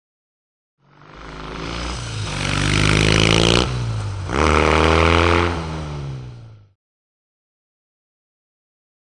Motorcycle; By; Triumph Twin Up And Past Mic. With Mid Shot Change Down And Quick Roar Away.